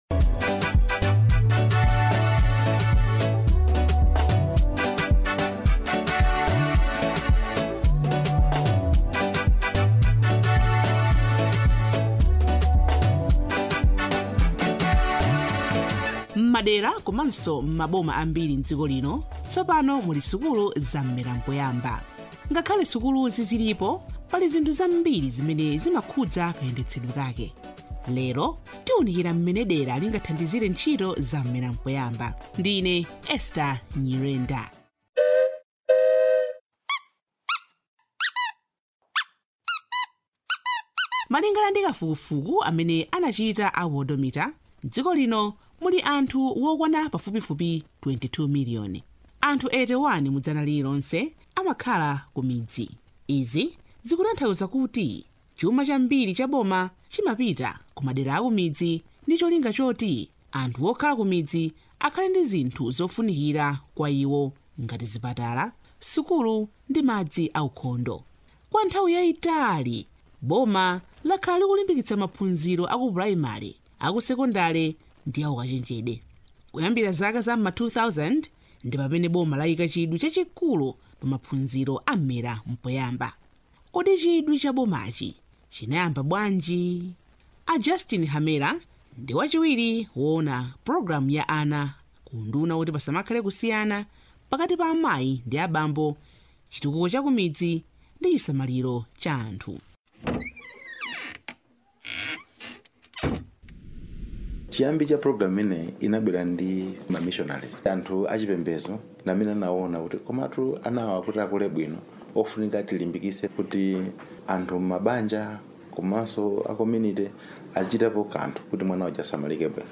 DOCUMENTARY ON COMMUNITY INVOLVEMENT
DOCUMENTARY ON COMMUNITY INVOLVEMENT.mp3